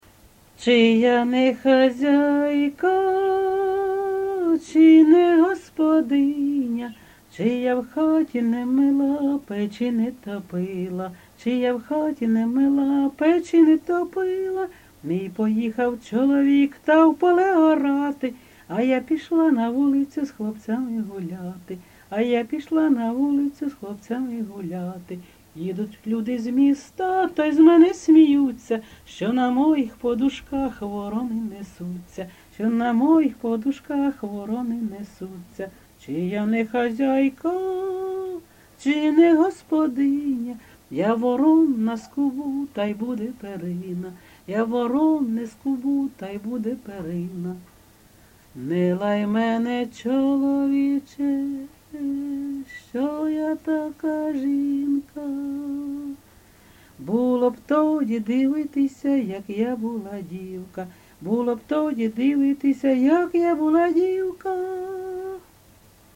ЖанрЖартівливі
Місце записус. Серебрянка, Артемівський (Бахмутський) район, Донецька обл., Україна, Слобожанщина